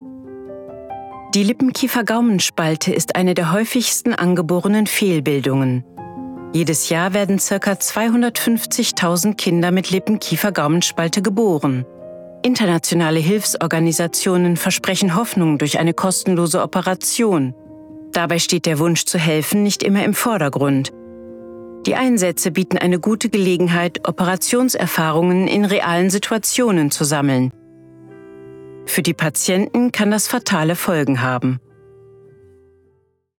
Kommentar (Demo)
warm, markant, tief, beruhigend, erzählerisch, vernünftig, psychologisch, seriös
Kommentar-Solo-LKGS-m-Musik-1.mp3